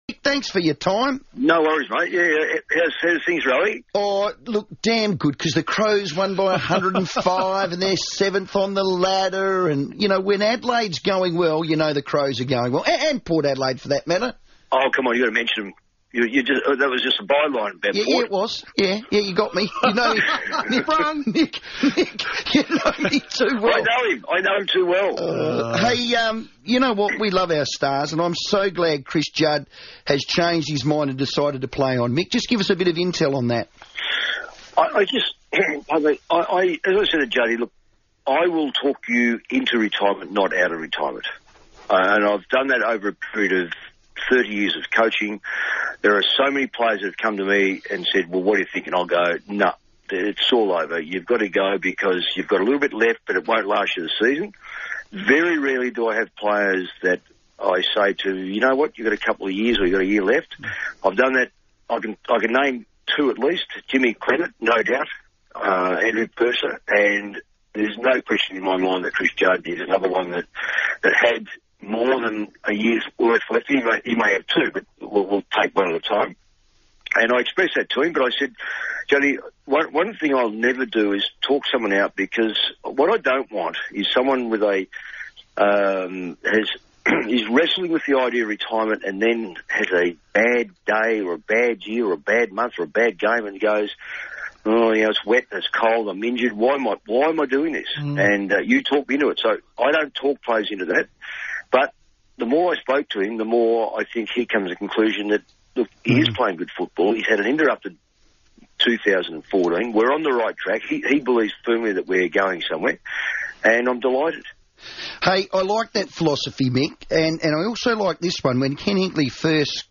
Senior Coach Mick Malthouse spoke to Adelaide's FIVEaa on Tuesday, August 12.